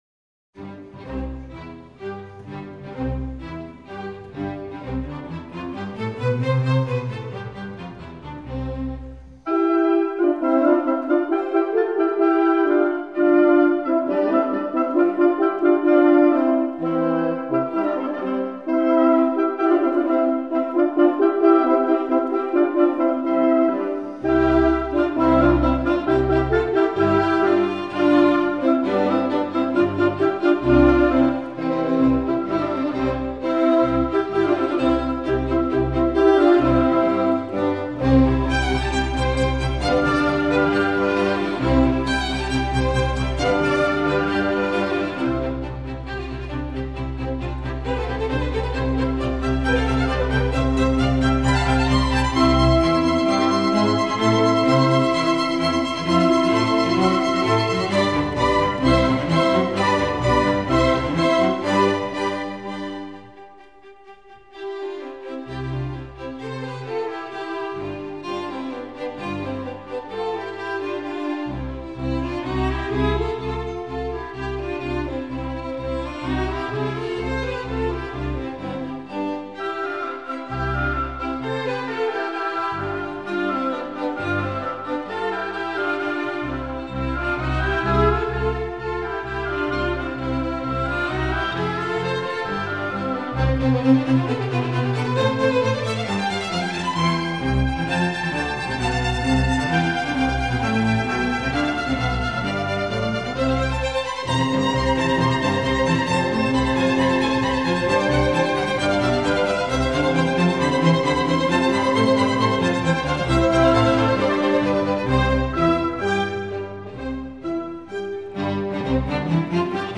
Slovak Chamber Orchestra
in C Major
bassoon